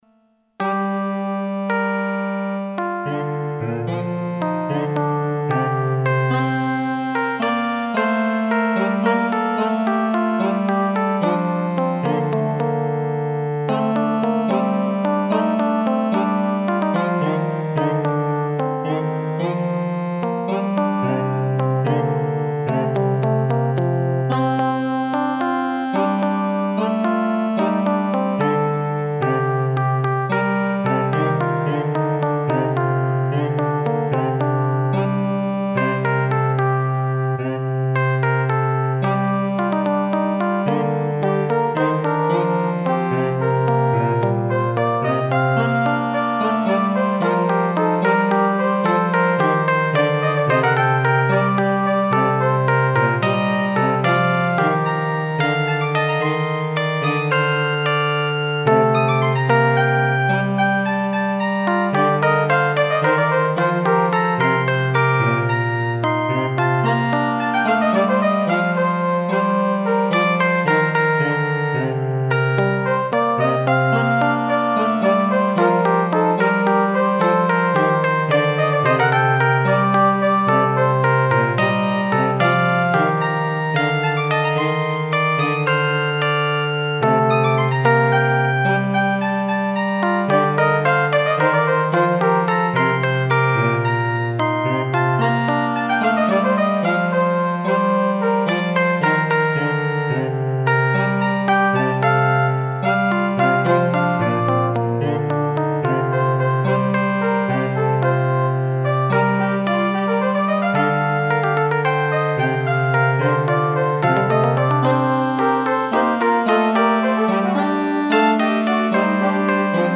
The bass line was drawn after the bass line of La harpe de melodie, by Jacob de Senleches.